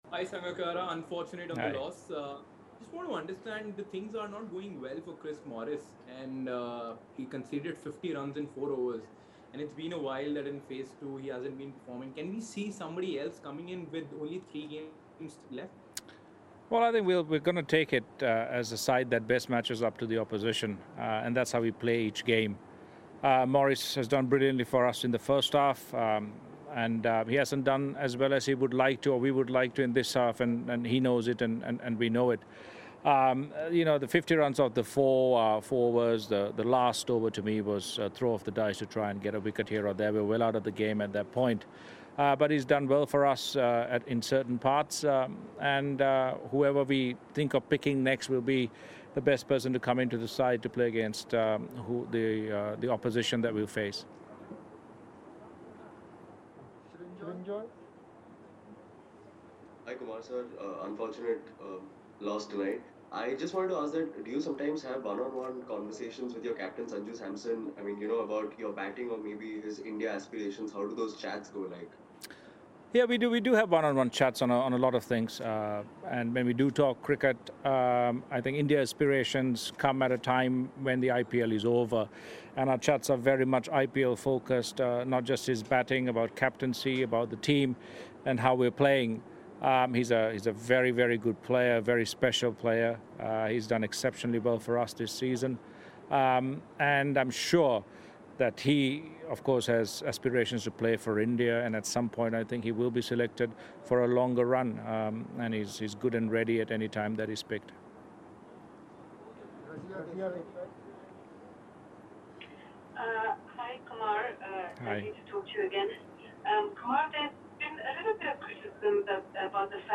Kumar Sangakkara speaks after Royal Challengers Bangalore beat Rajasthan Royals
Kumar Sangakkara, Director of Cricket of Rajasthan Royals & KS Bharat of Royal Challengers Bangalore addressed the media at the end of the game.